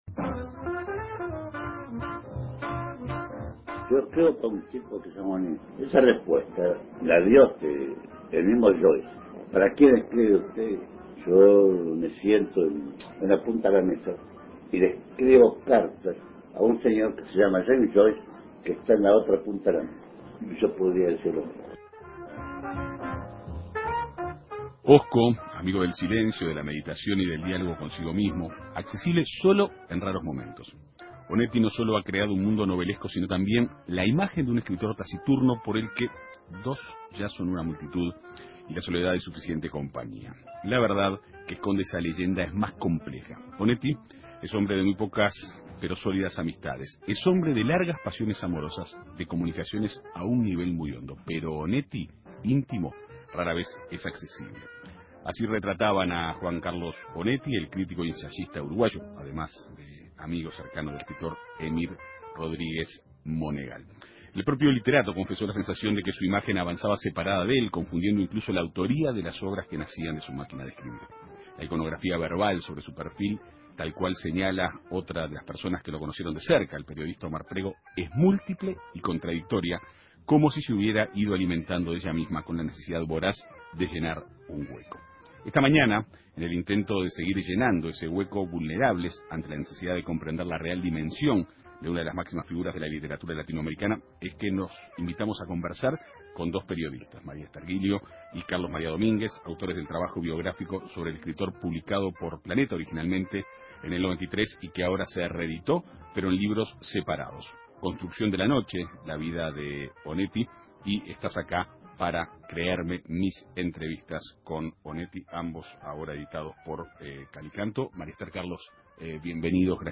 En Perspectiva Segunda Mañana, en el intento de seguir llenando ese hueco, dialogó con María Esther Gilio y Carlos María Domínguez, autores del trabajo biográfico sobre el escritor.